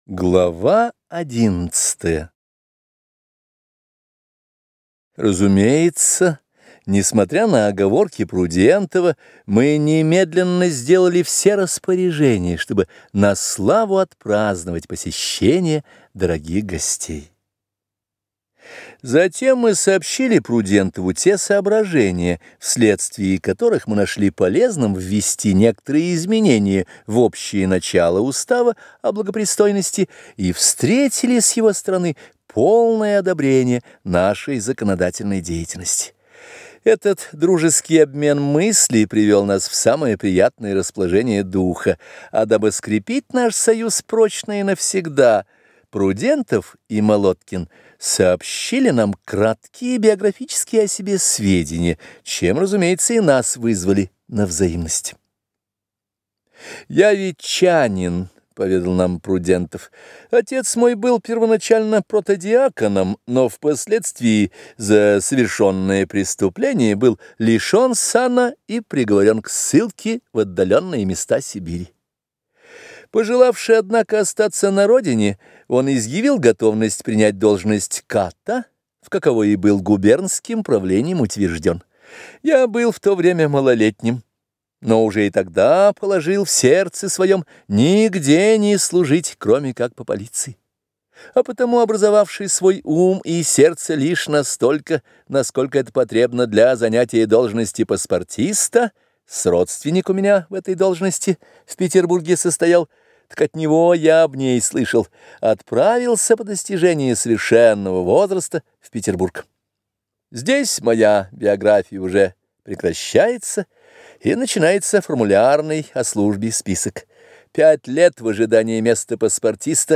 Аудиокнига Современная идиллия | Библиотека аудиокниг